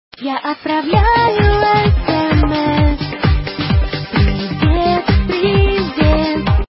Один из любимых мной сигнал на смс.Качайте и ставте на смс.